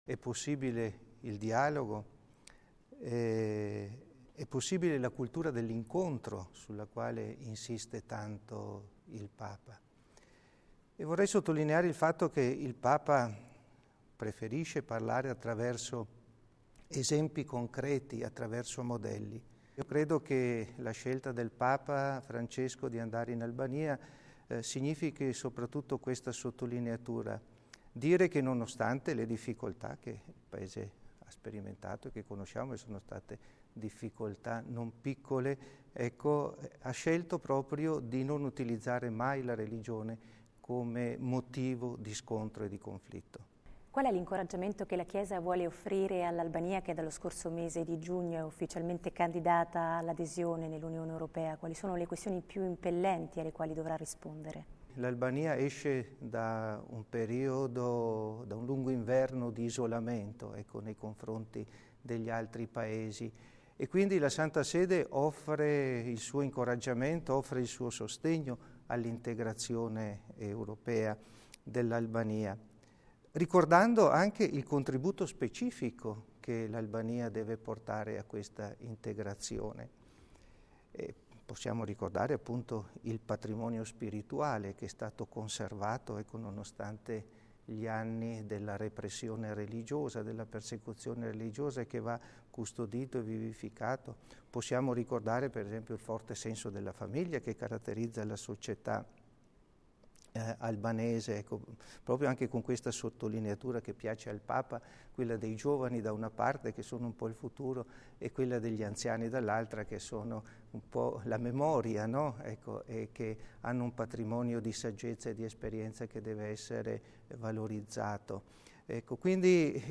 Alla vigilia del viaggio di Papa Francesco in Albania, domenica 21 settembre, il cardinale segretario di Stato Pietro Parolin illustra - in una intervista rilasciata al Centro Televisivo Vaticano - i principali temi al centro dell’evento. Primo fra tutti, dopo gli anni bui del regime, la capacità di Tirana di aver scelto la via del dialogo con un governo di unità nazionale tra islamici, ortodossi e cattolici.